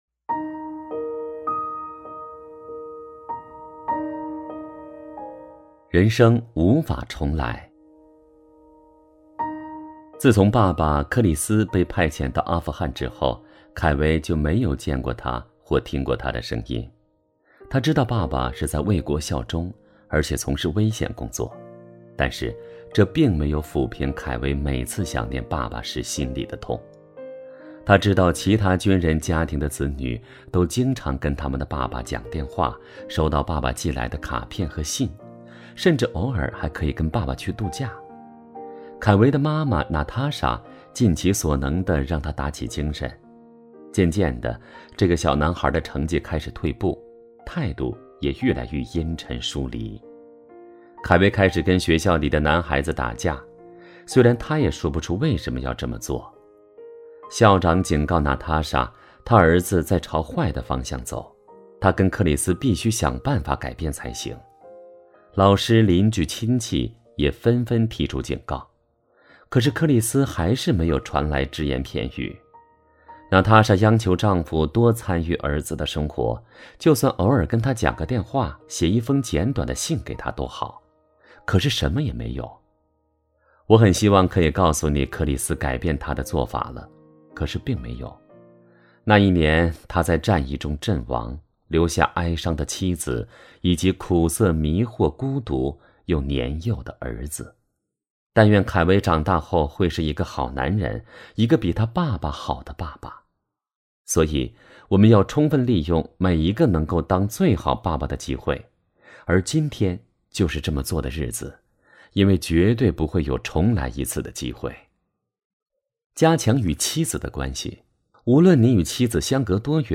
首页 > 有声书 > 婚姻家庭 | 成就好爸爸 | 有声书 > 成就好爸爸：41 人生无法重来